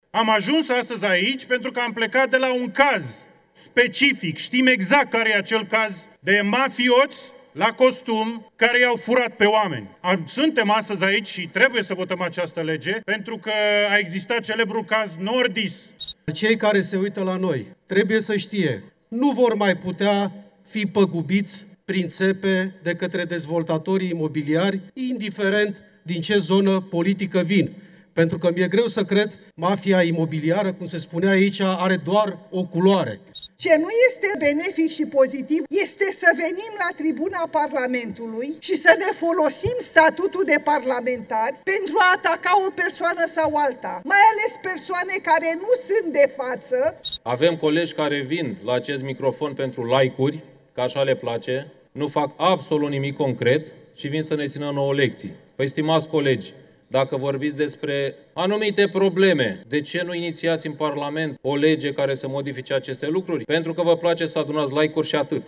Scandalul a fost readus în plenul Camerei Deputaților:
Deputatul AUR Dan Tănasă: „Trebuie să votăm această lege pentru că a existat celebrul caz Nordis”
Deputatul PSD Ștefan Popa: „Avem colegi care vin la acest microfon pentru like-uri”